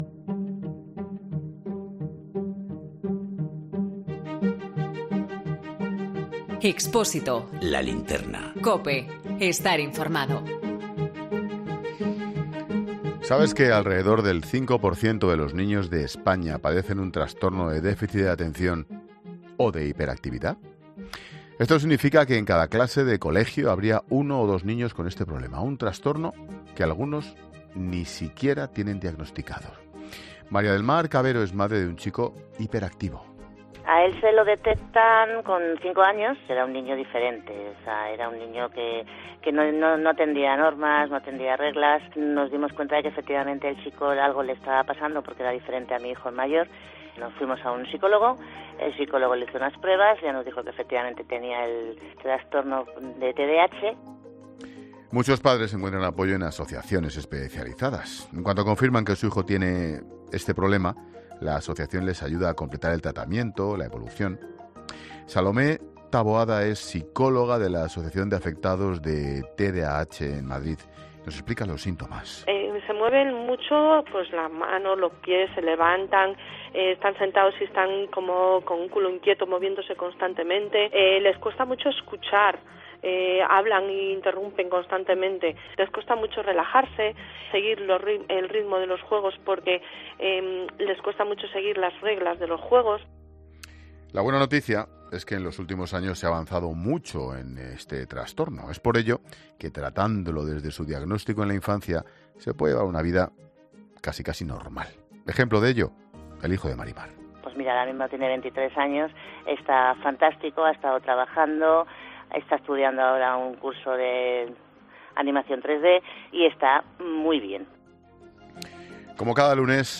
Como cada lunes toca hablar de salud. Hoy nos centramos en el Trastorno de Hiperactividad.